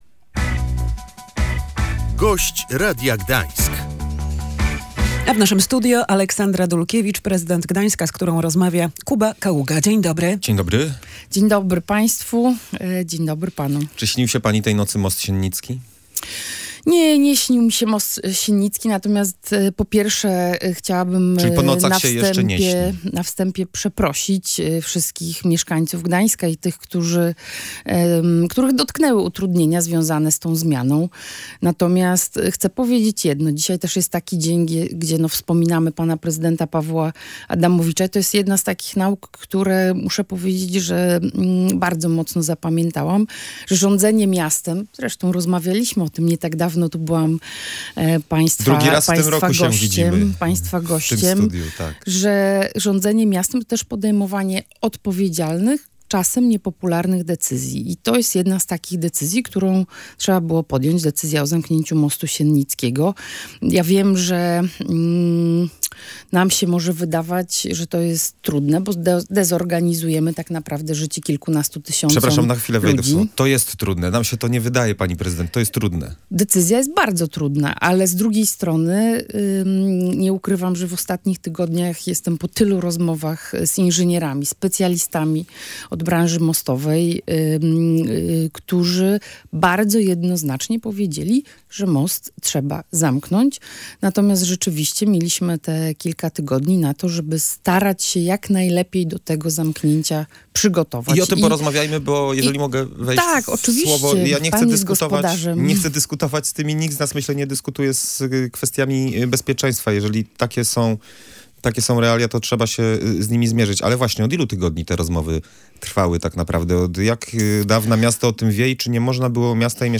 Jeszcze w tym miesiącu może zacząć działać prom dla mieszkańców Przeróbki - mówiła w Radiu Gdańsk prezydent Gdańska Aleksandra Dulkiewicz.